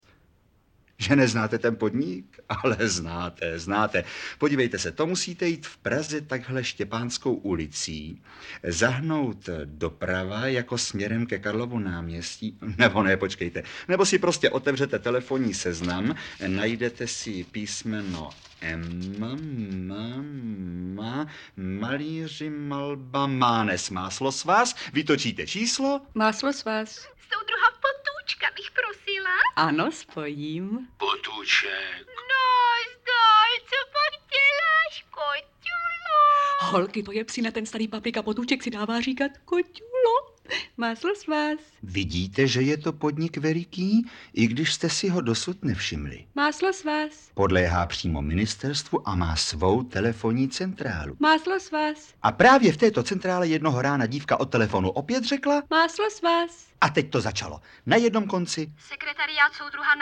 Audiobook
Read: Lubomír Lipský